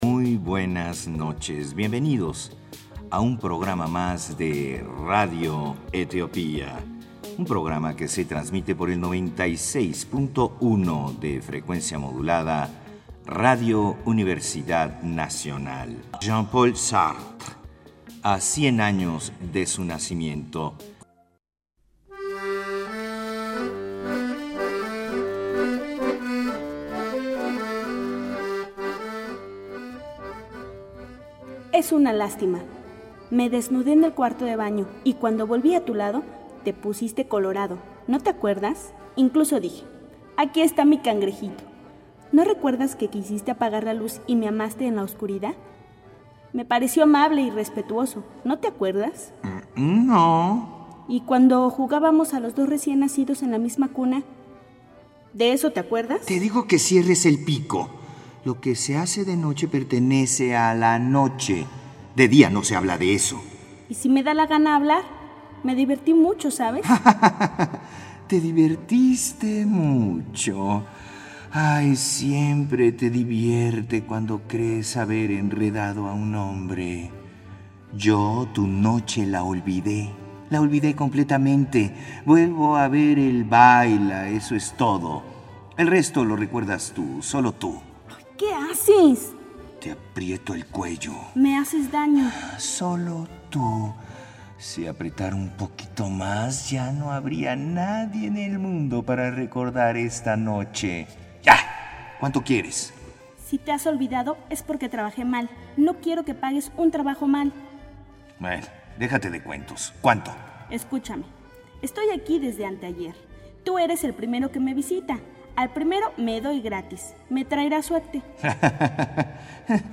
Te presentamos dos fragmentos de una divertida obra de Jean Paul Sartre